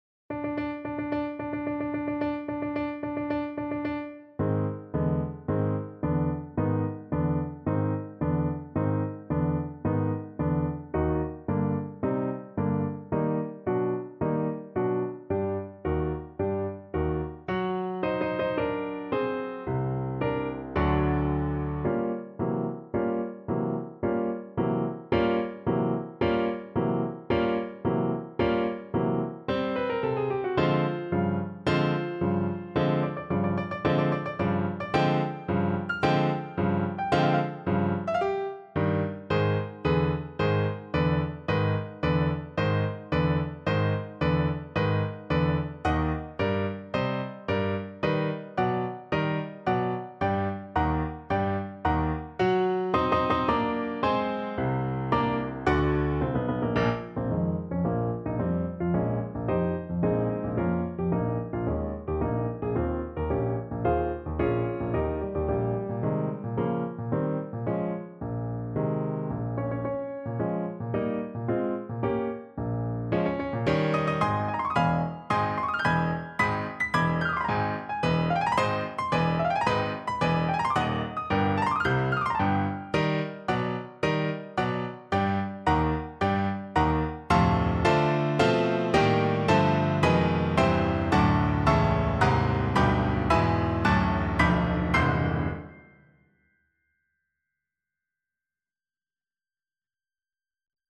~ = 110 Tempo di Marcia